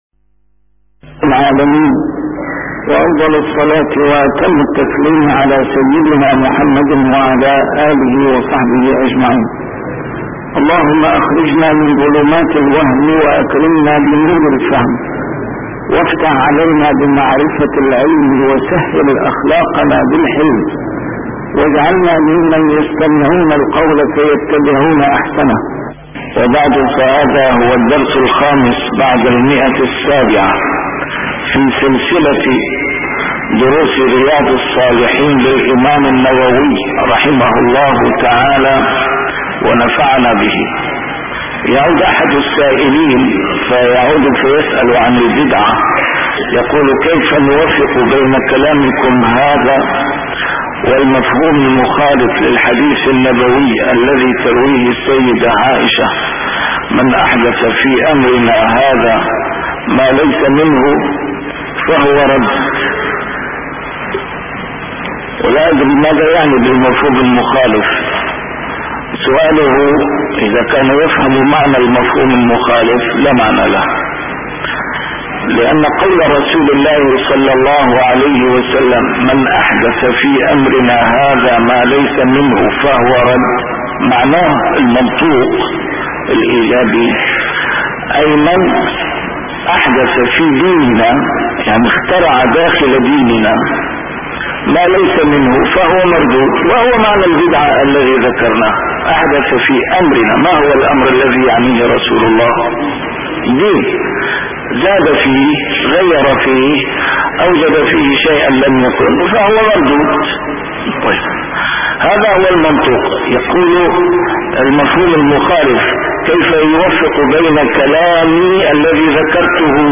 A MARTYR SCHOLAR: IMAM MUHAMMAD SAEED RAMADAN AL-BOUTI - الدروس العلمية - شرح كتاب رياض الصالحين - 705- شرح رياض الصالحين: فيما يقوله من أيس من حياته